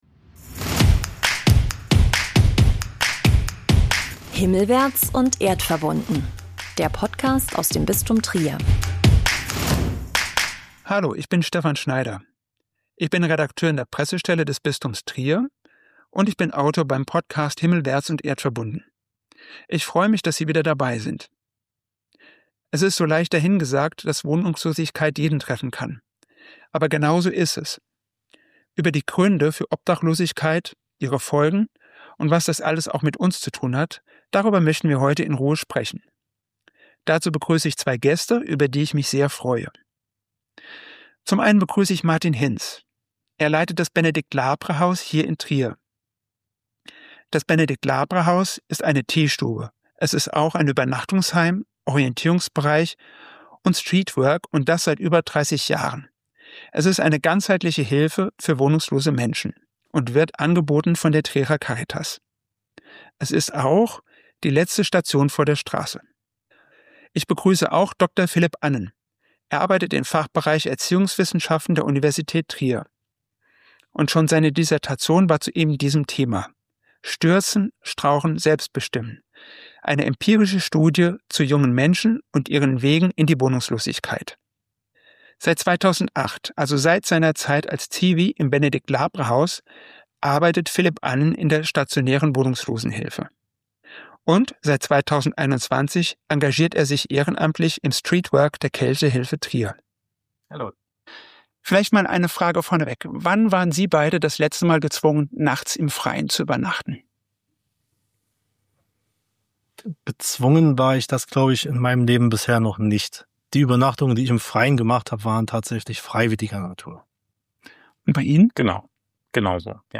spricht darüber mit Fachleuten von Caritas und Uni in Trier.